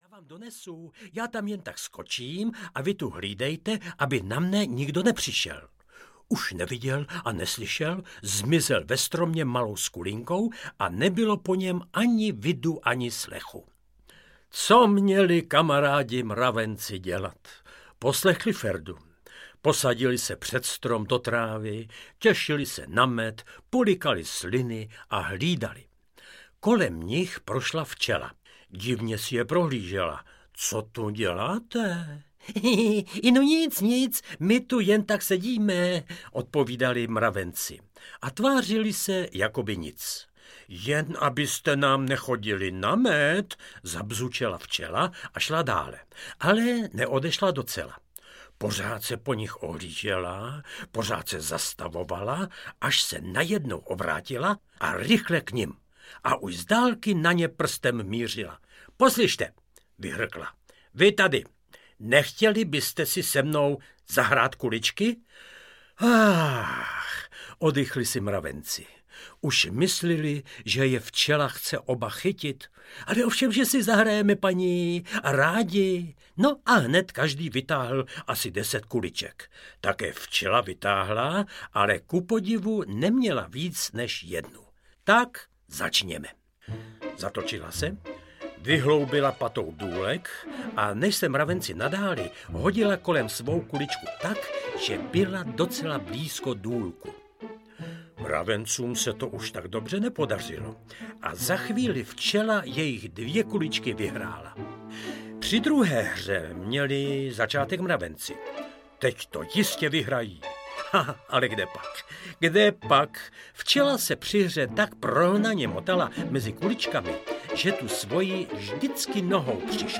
Ukázka z knihy
Vypráví Lubomír Lipský.